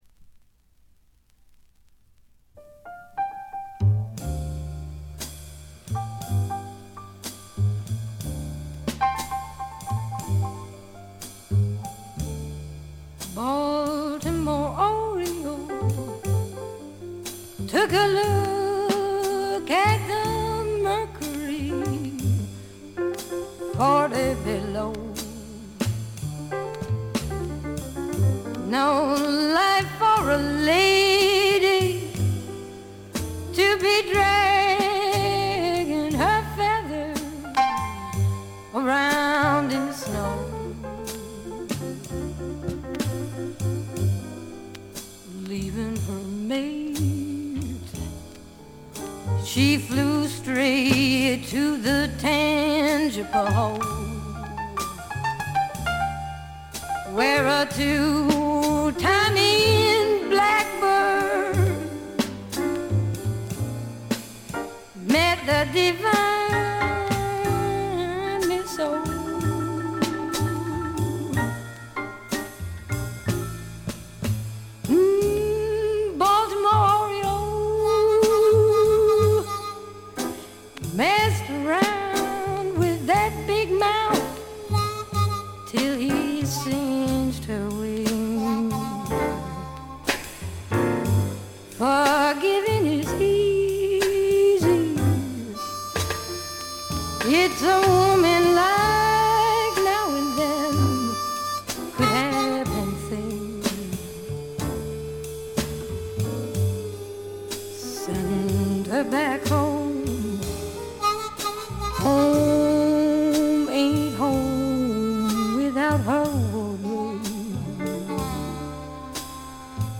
微細なノイズ感のみ。
ここでの彼女はみずからギターを弾きながら歌う渋い女性ブルースシンガーという側面を見せてくれます。
激渋アコースティック・ブルースにオールドジャズやR&Bのアレンジが施されたサウンド作りもいい感じですね。
試聴曲は現品からの取り込み音源です。